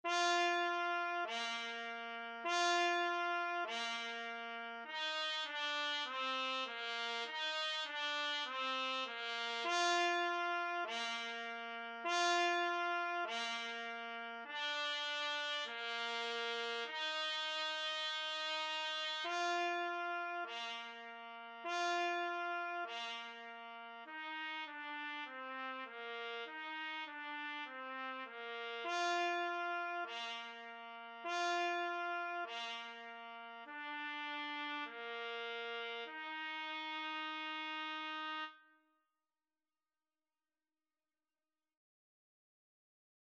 4/4 (View more 4/4 Music)
Bb4-F5
Beginners Level: Recommended for Beginners
Instrument:
Classical (View more Classical Trumpet Music)